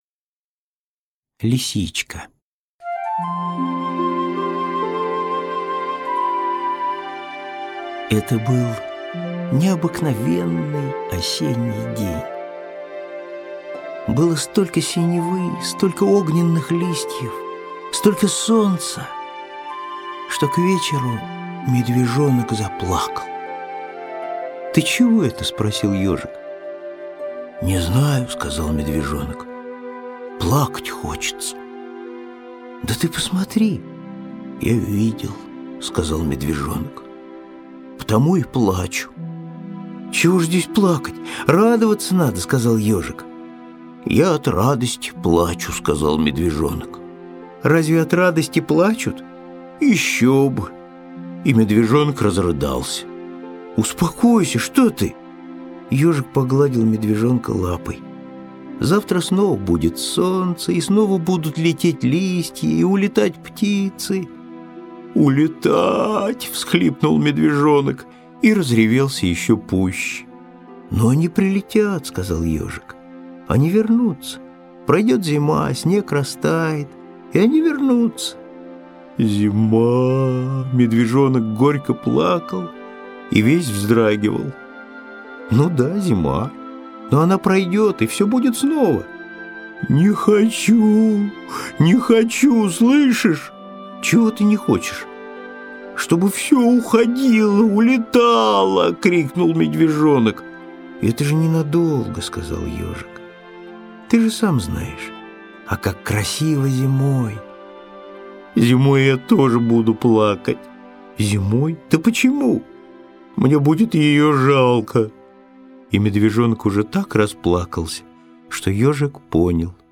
Лисичка – Козлов С.Г. (аудиоверсия)
Аудиокнига в разделах